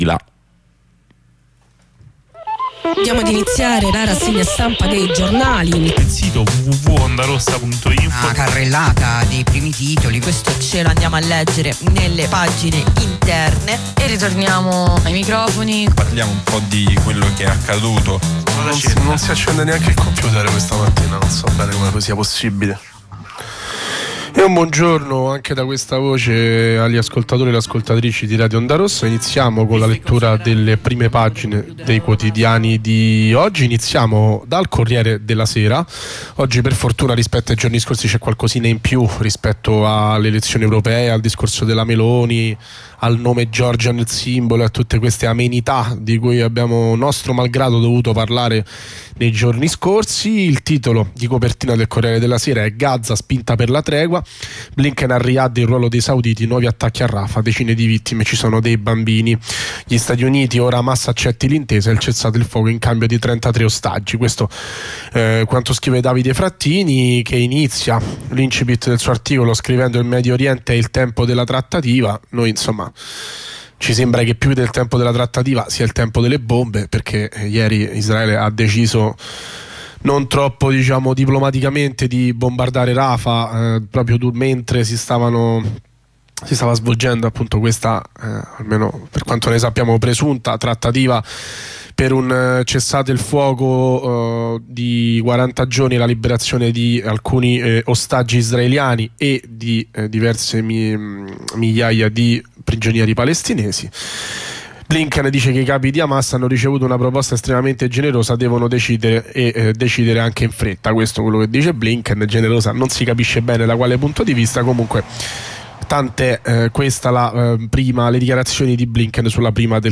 Lettura e commento dei quotidiani.